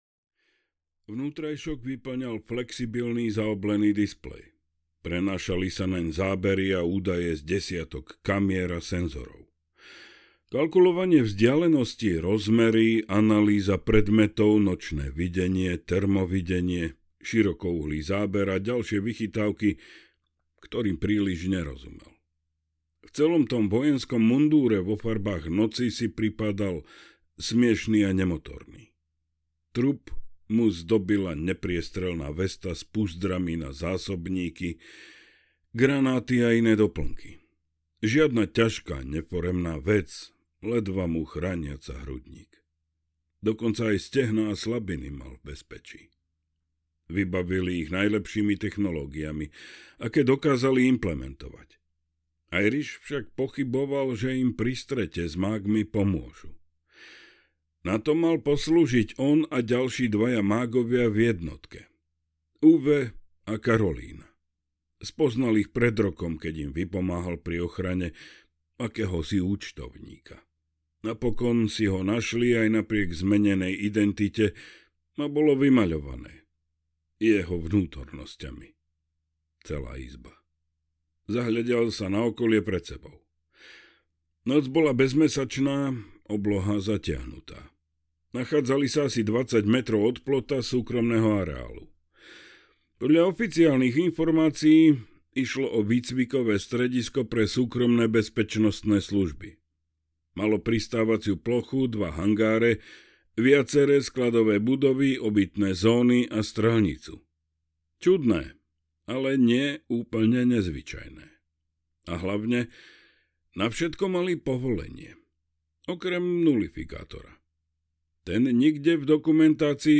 Lovec audiokniha
Ukázka z knihy